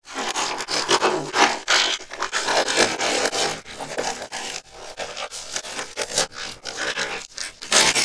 MONSTERS_CREATURES
ALIEN_Communication_14_mono.wav